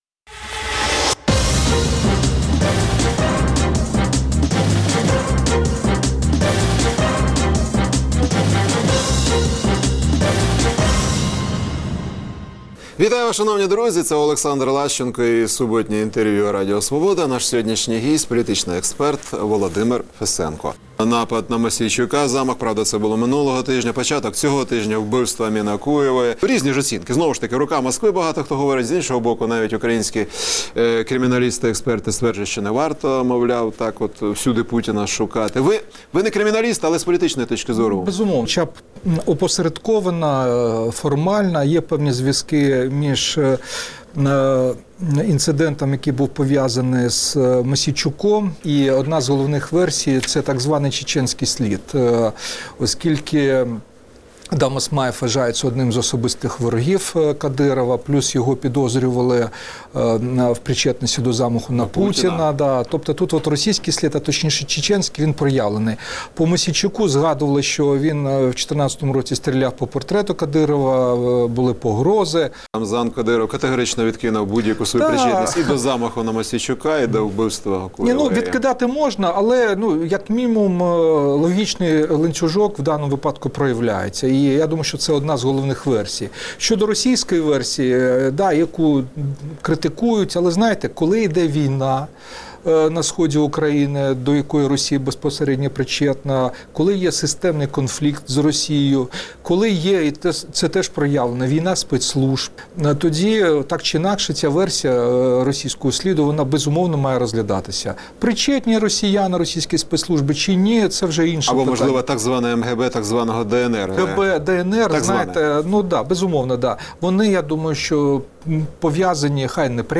Суботнє інтерв’ю - Володимир Фесенко, політолог